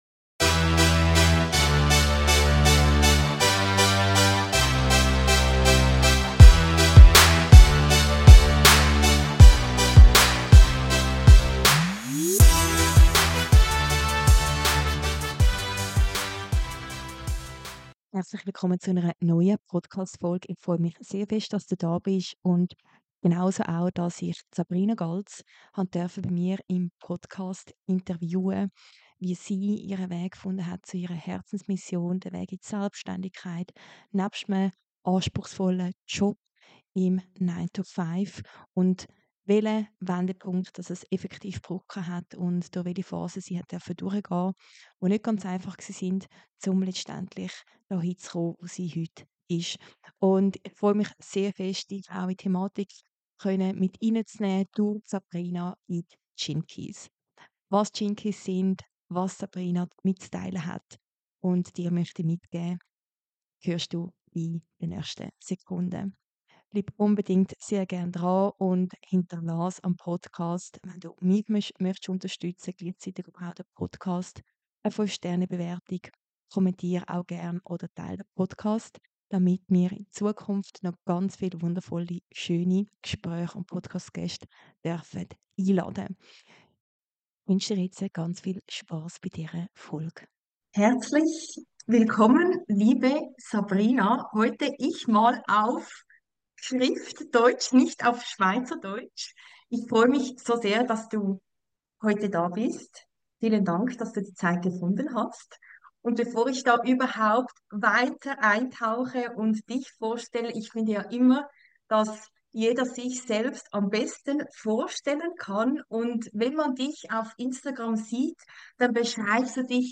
Lehn dich zurück und lass dich mitnehmen in ein Gespräch voller Tiefe, Vertrauen und neuer Impulse.